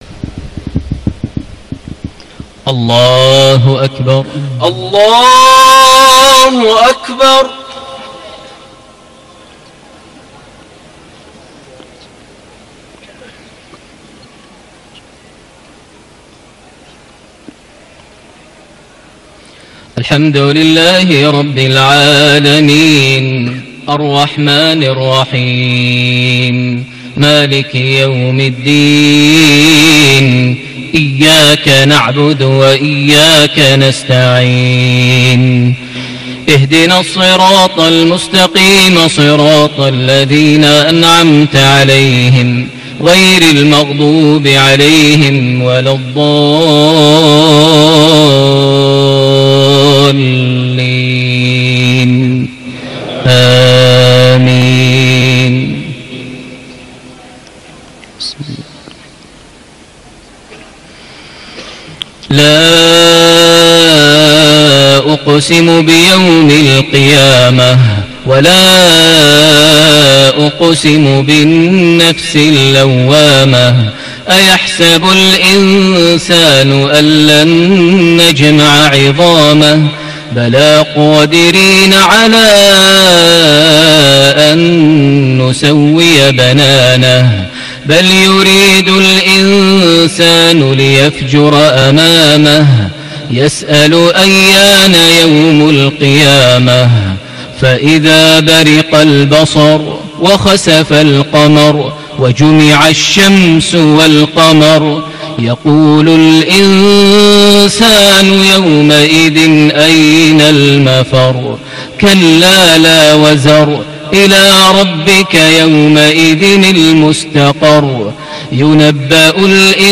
lsha 1-4-2017 Surah Al-Qiyama - Surah Al-Balad > 1438 H > Prayers - Maher Almuaiqly Recitations